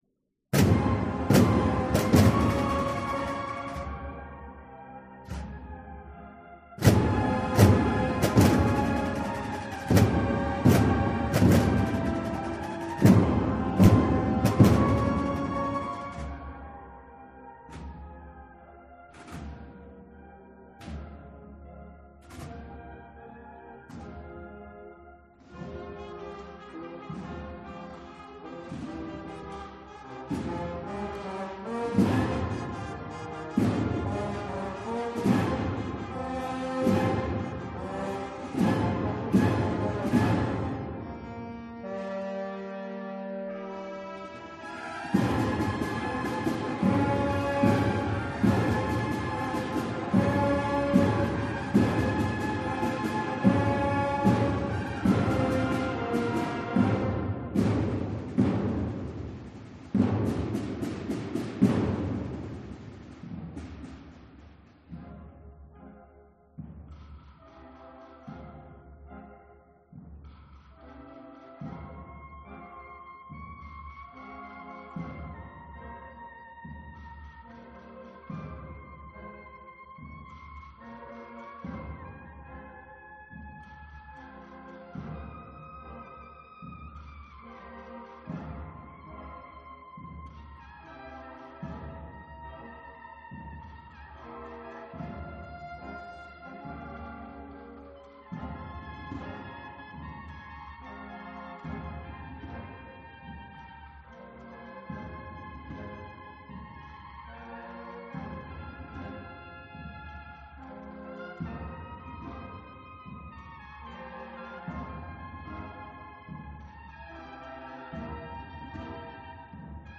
marcha
BANDA EXPIRACIÓN
Se ha interpretado por primera vez en el concierto de presentación de la Banda de CC y TT de la Expiración
La Parroquia de San José de Calasanz ha acogido este domingo, 28 de noviembre, el concierto de presentación de la Banda de CC y Tambores de la Expiración (Escolapios).